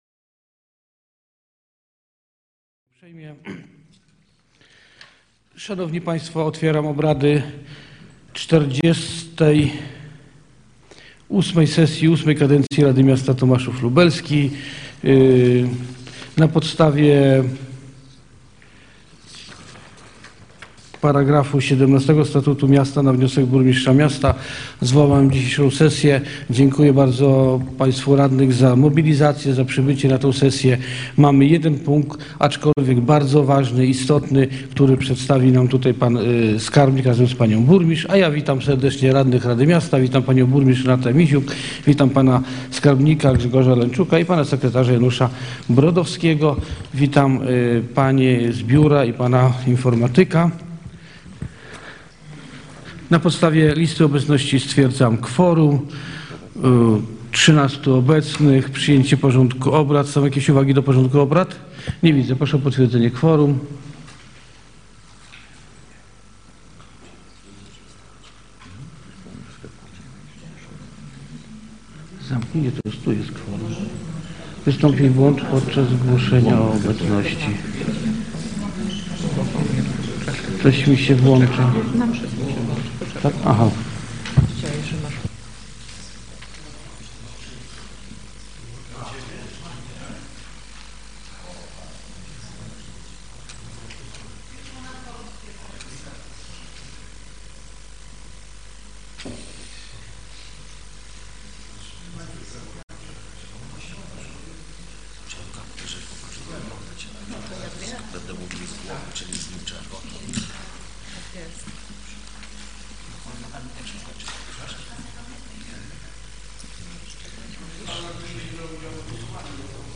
Nagranie z posiedzenia
XLVIII sesja Rady Miasta w dniu 23 września 2022, godz. 07:40, w sali konferencyjnej Urzędu Miasta, przy ul. Lwowskiej 57 w Tomaszowie Lubelskim